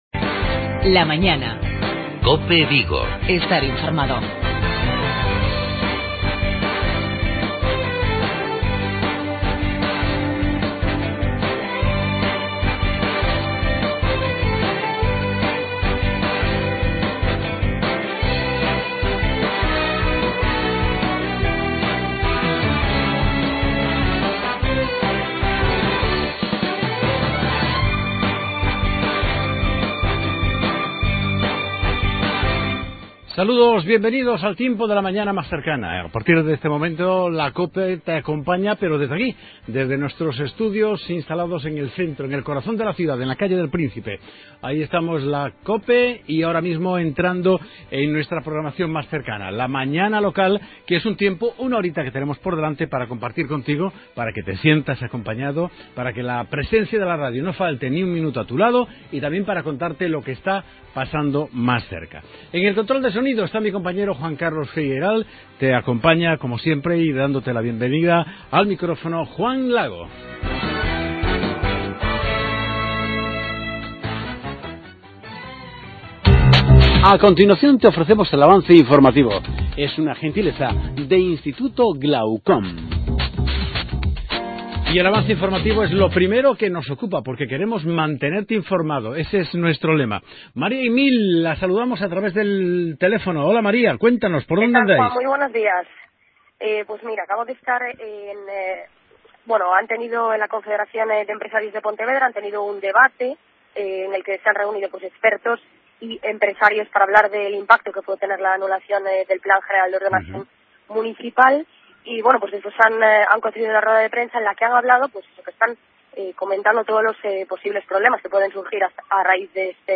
Entrevista a Alberto Pazos, Director Xeral de Administración Local, sobre el encuentro con el Alcalde de Vigo, en relación al Area Metropolitana.